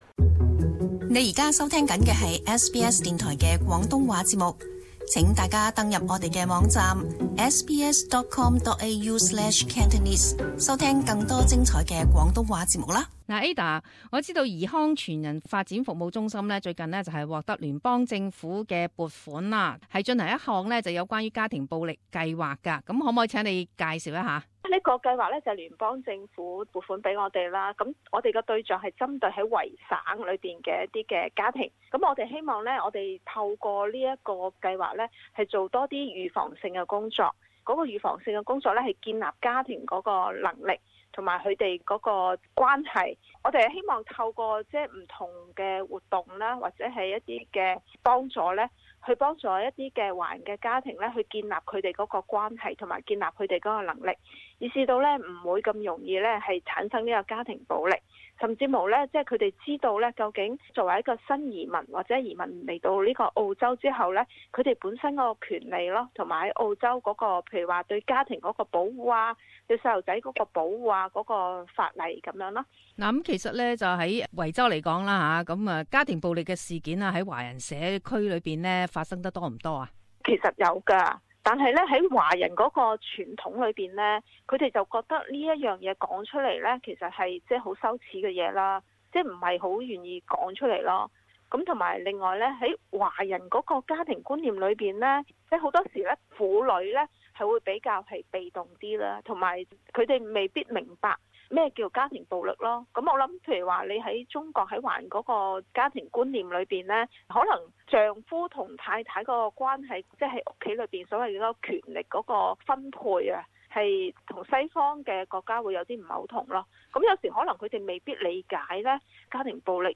【社团专访】华人家庭暴力如何处理